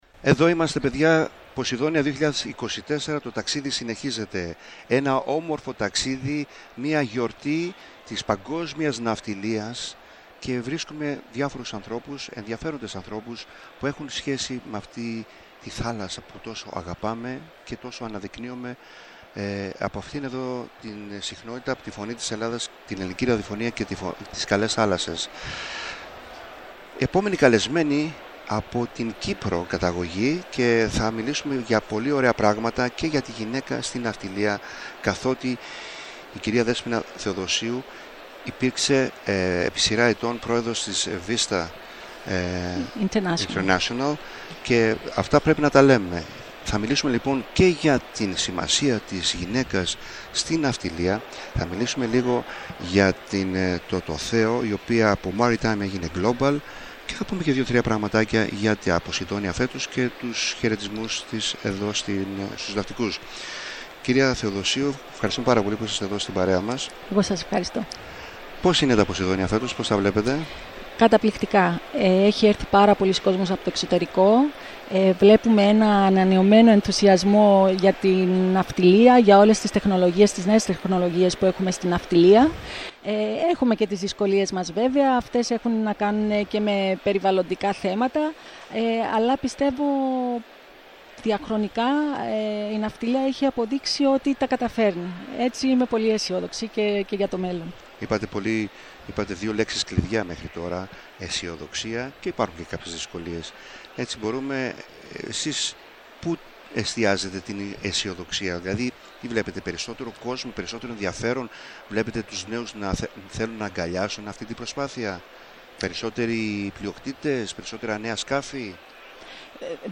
Η ΦΩΝΗ ΤΗΣ ΕΛΛΑΔΑΣ Καλες Θαλασσες ΣΥΝΕΝΤΕΥΞΕΙΣ Συνεντεύξεις